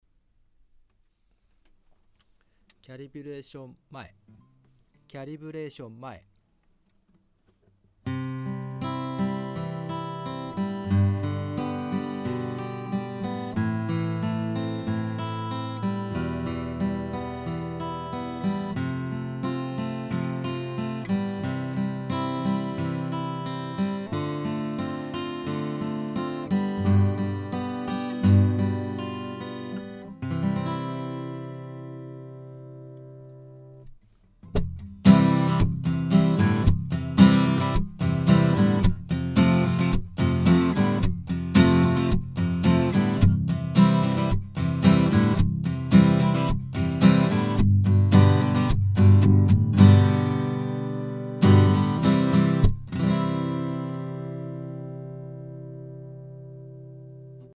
直接オーディオIFに入れてエフェクト類は全くかけていません。
まずはマイクで録音した音
少しぼんぼんしてますが、こんな感じの音です。
マイクで録音
iRig-BM-800.mp3